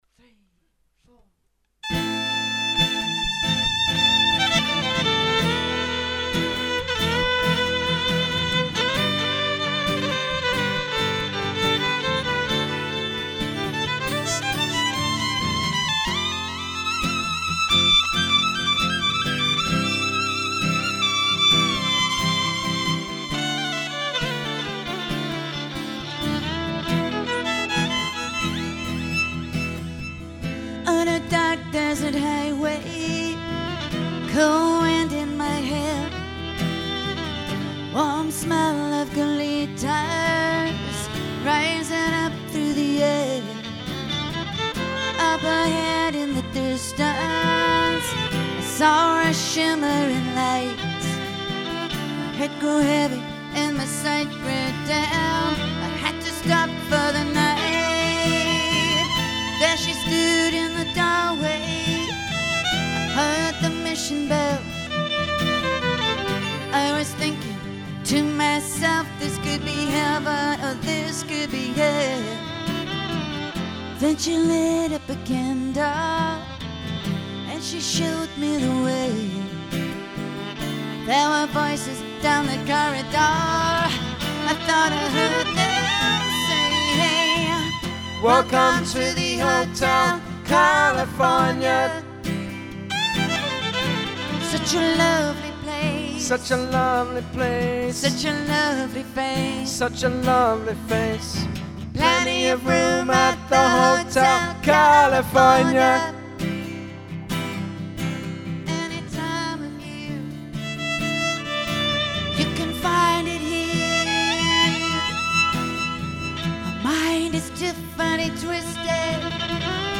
acoustic guitar
electric violin
Audio Clips of the duo recorded live (MP3s):-